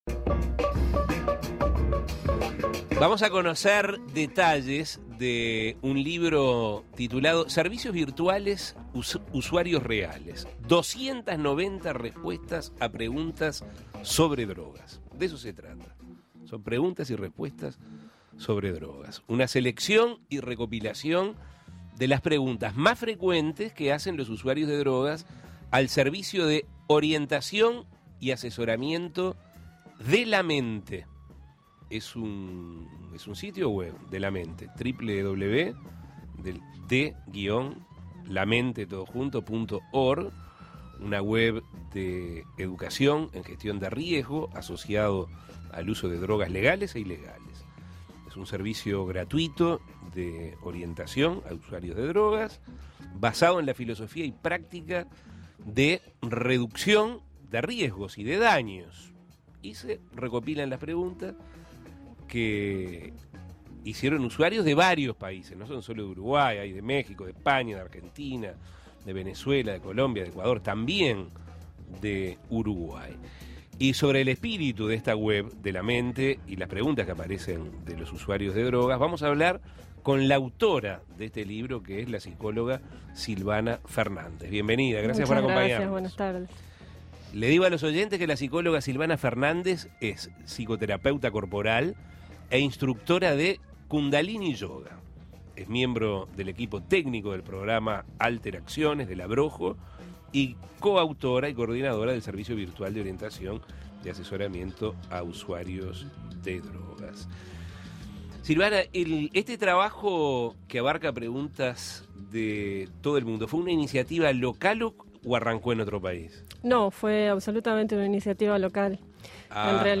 Se trata de una selección y recopilación de las preguntas más frecuentes realizadas por usuarios de drogas a dicha organización. Escuche la entrevista.